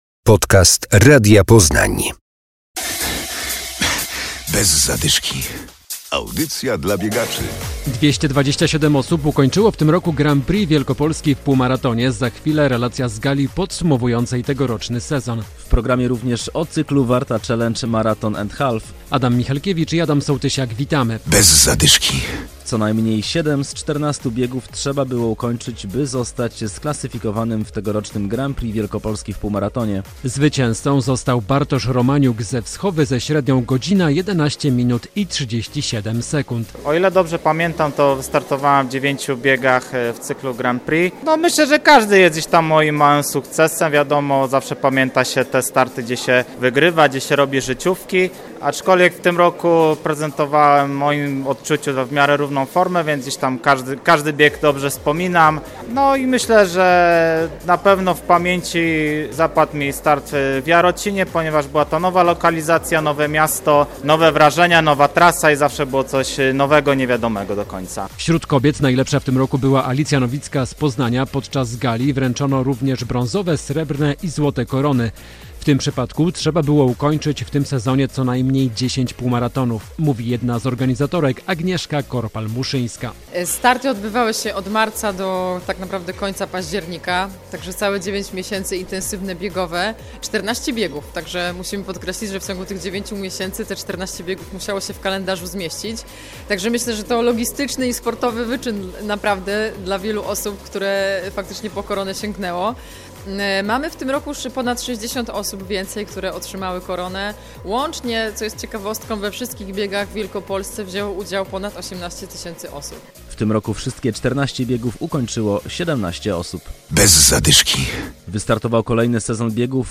Relacja z gali Grand Prix Wielkopolski w Półmaratonie.